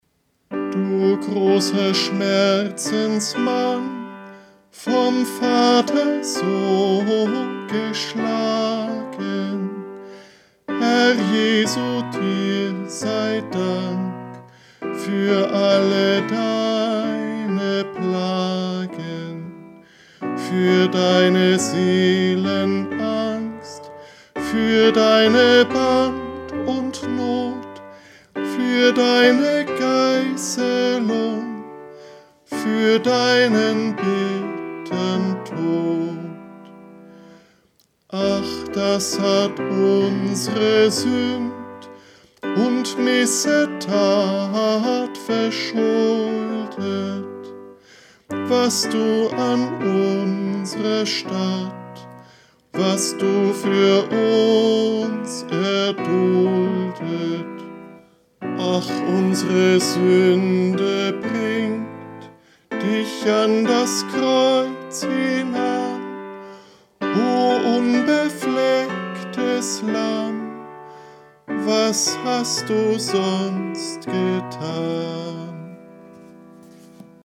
Eingesungen: Liedvortrag (EG 87, 1. und 2.